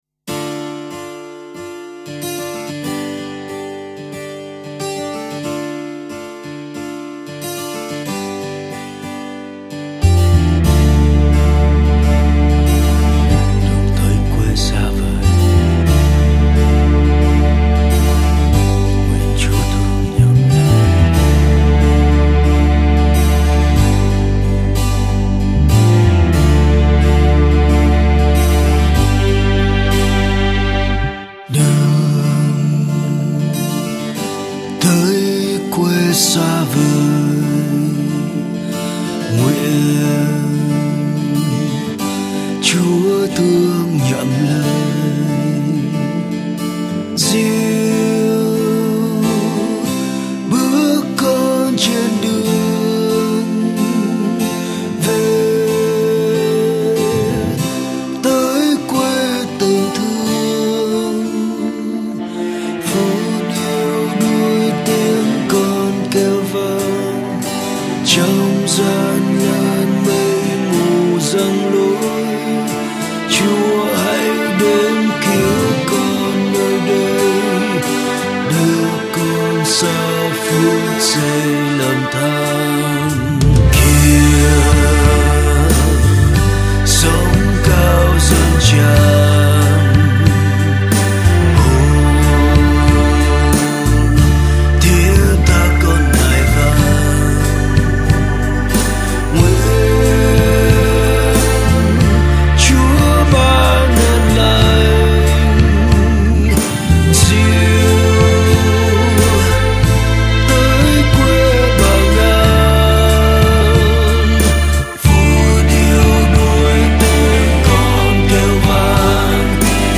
Guitars
Hát bè